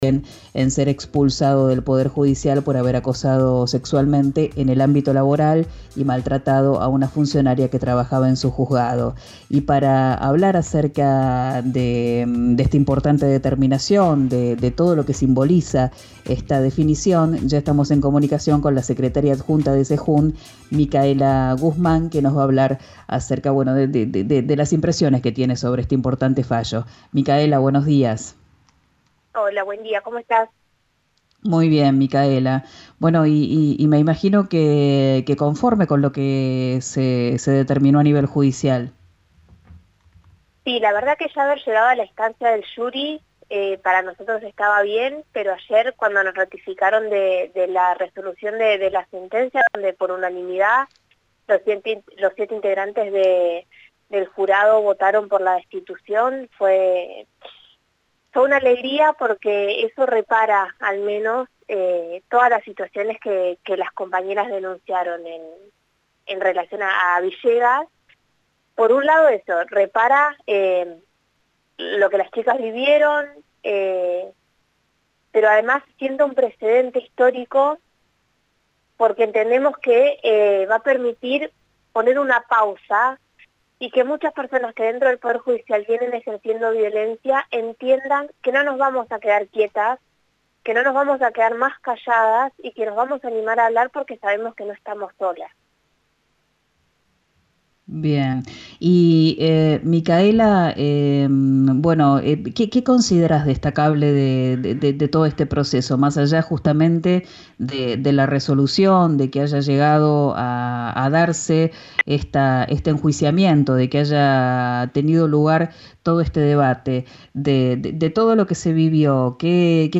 En diálogo con RÍO NEGRO RADIO, la dirigenta sostuvo que la sentencia unánime representa «un precedente histórico».